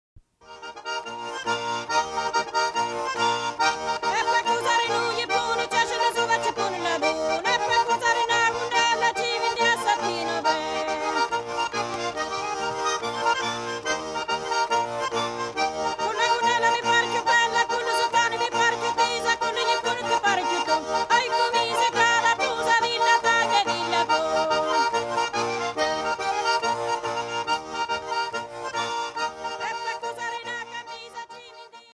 In this ironic sing of courtship, accompanying the tarantella, a young man surveys his future bride with a sharp eye.
sings with the acute, throaty voice that makes Calabrian women in animated conversation sound like a flock of exotic birds.
plays an heirloom concertina (c. 1900). Recorded in Belleville, New Jersey.